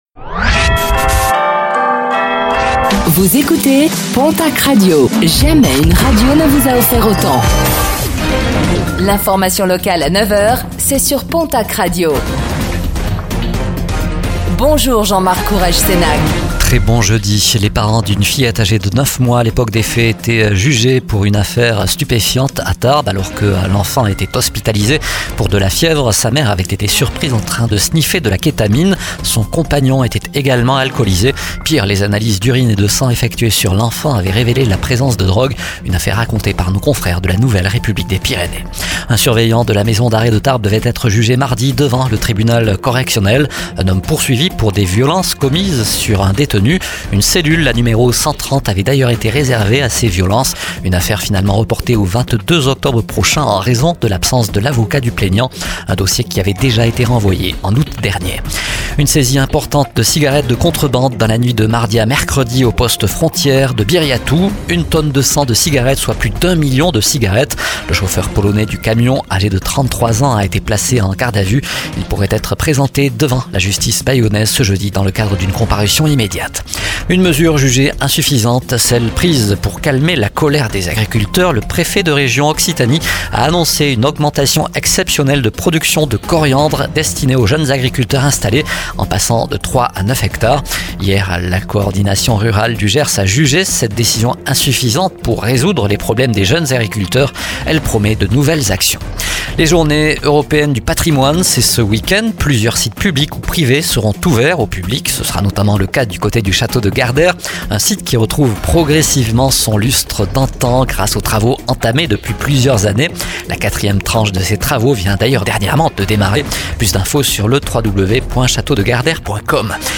Réécoutez le flash d'information locale de ce jeudi 19 septembre 2024